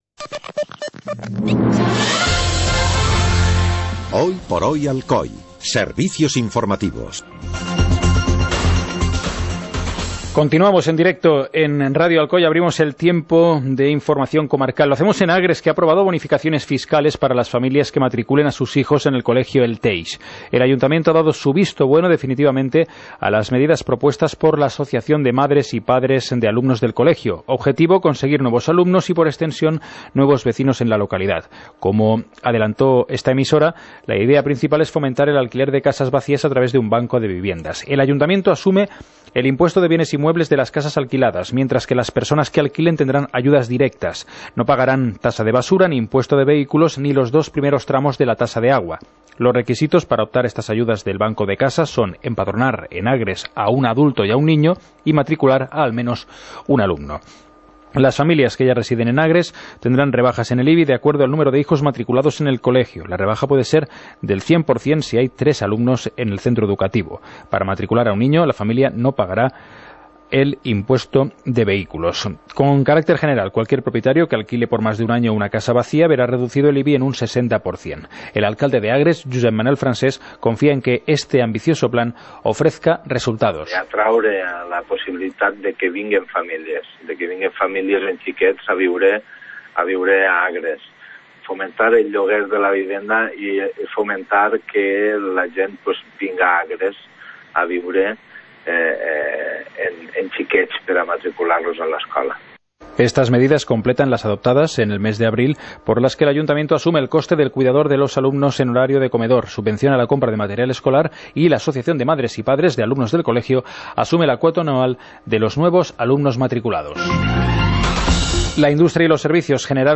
Informativo comarcal - miércoles, 06 de julio de 2016